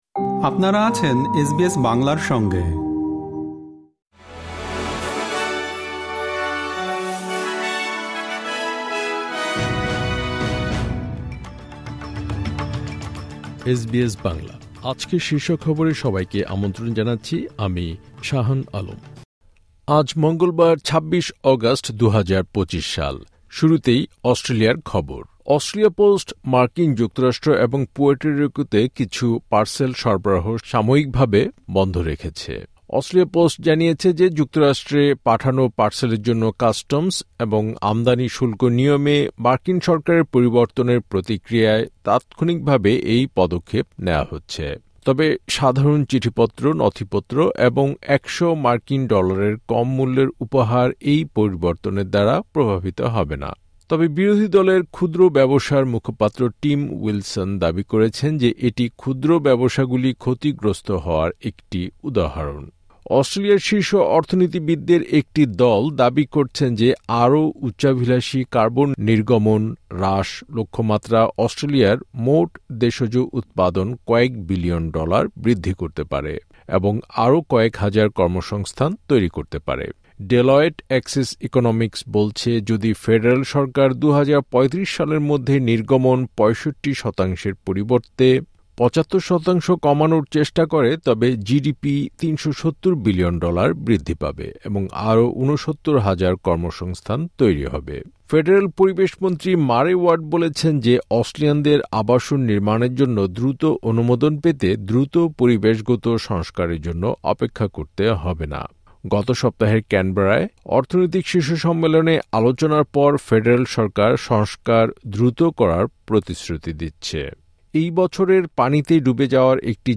এসবিএস বাংলা শীর্ষ খবর: ২৬ অগাস্ট, ২০২৫